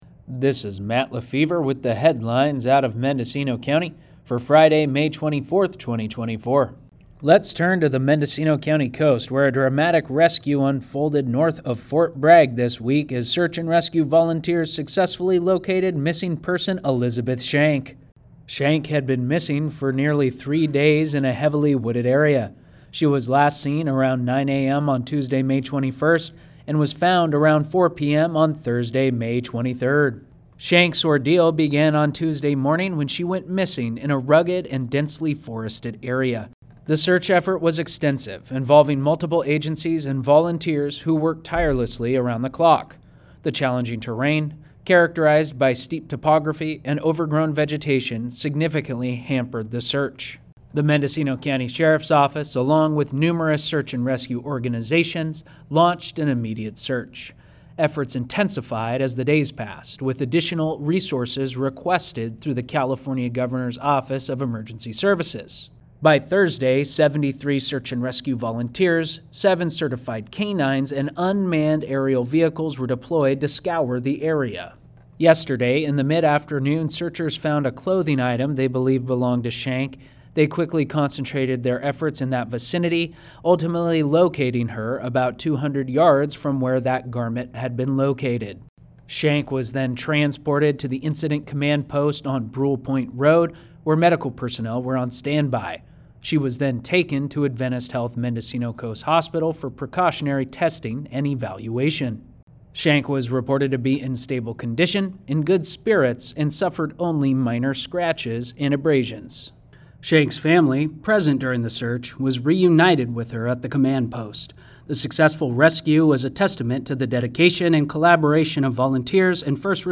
KMUD News